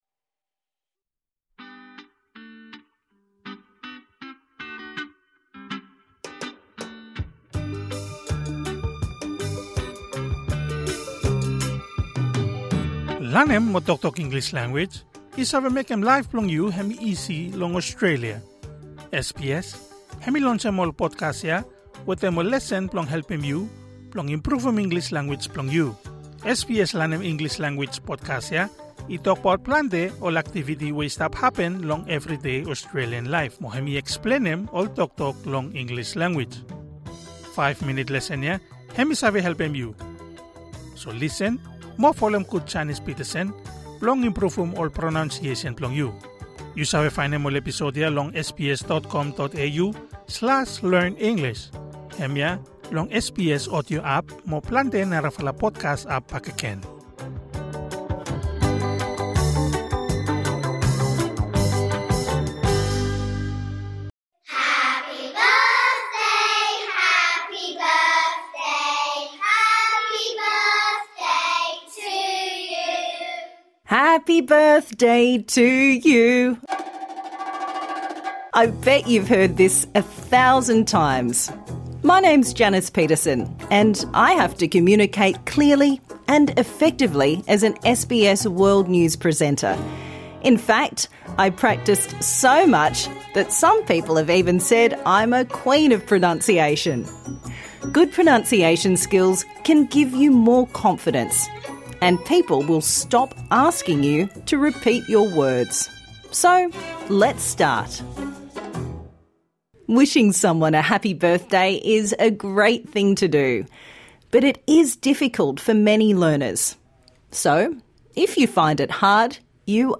P vs B - Pronunciation